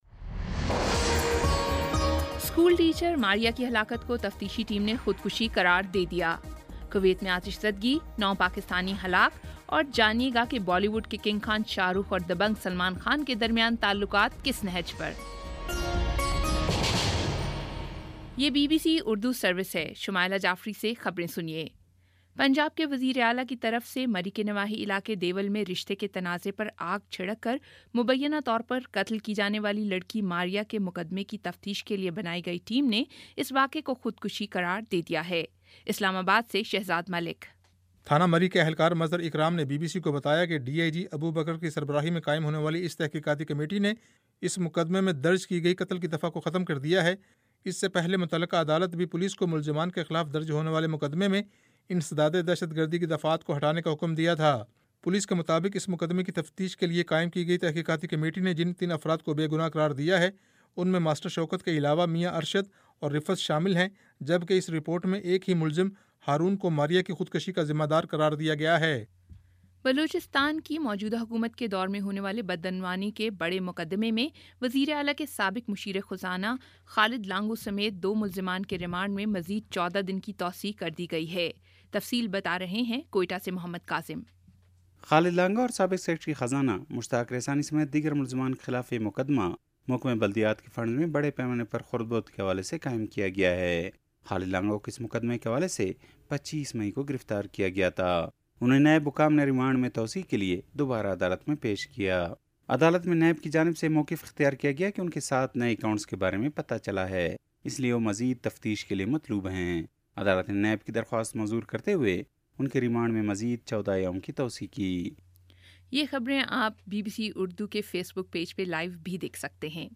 جولائ 01 : شام پانچ بجے کا نیوز بُلیٹن